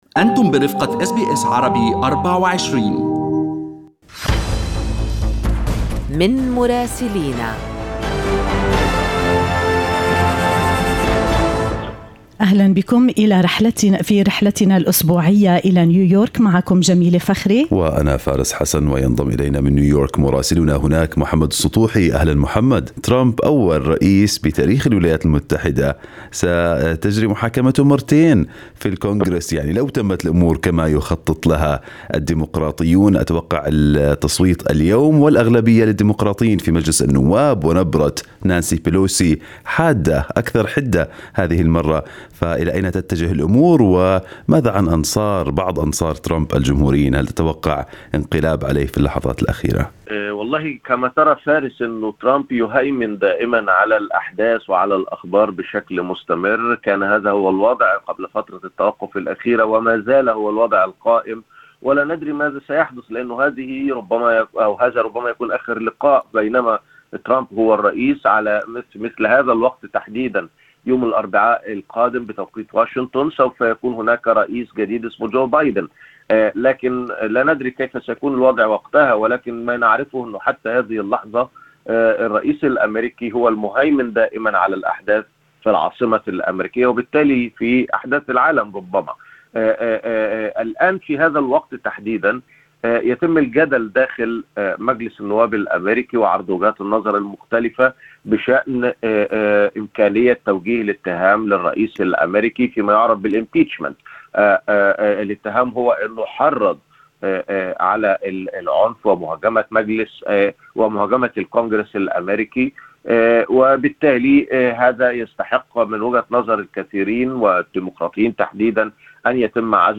يمكنكم الاستماع إلى تقرير مراسلنا في نيويورك بالضغط على التسجيل الصوتي أعلاه.